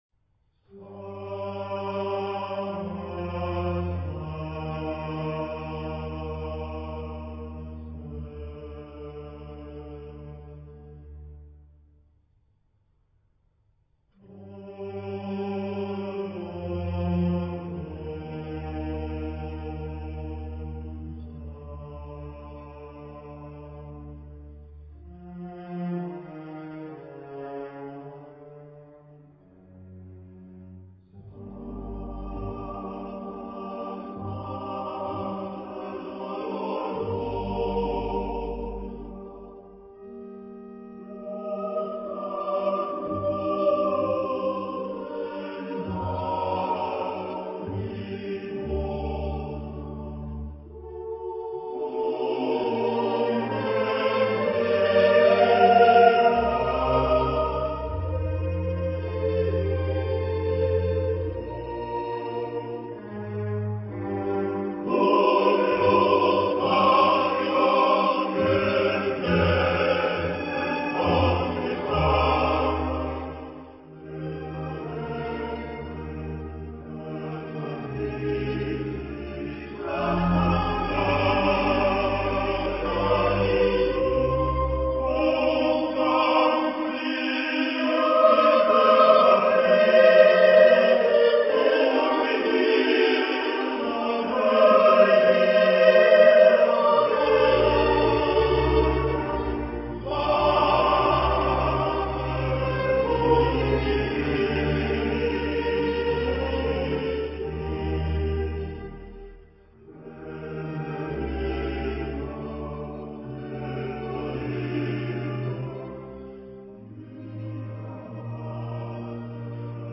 Genre-Style-Forme : Sacré ; Romantique
Type de choeur : SATB  (4 voix mixtes )
Tonalité : sol mineur